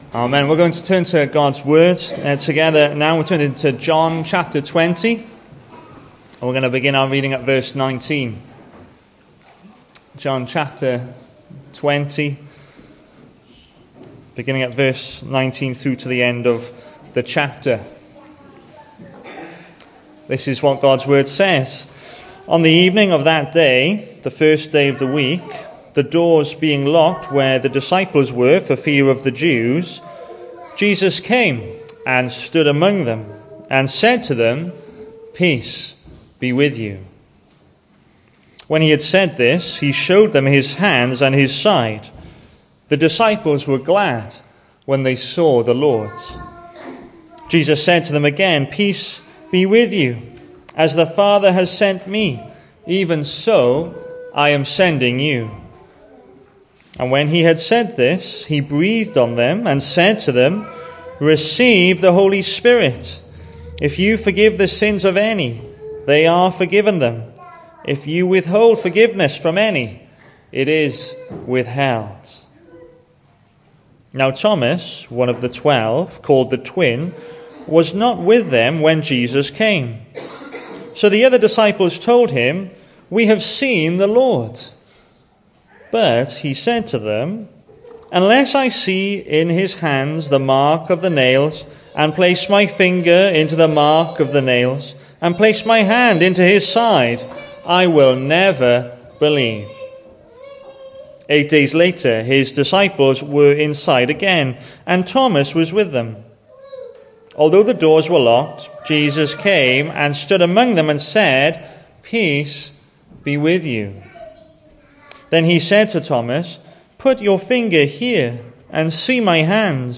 Hello and welcome to Bethel Evangelical Church in Gorseinon and thank you for checking out this weeks sermon recordings.
The 31st of March saw us hold our evening service from the building, with a livestream available via Facebook.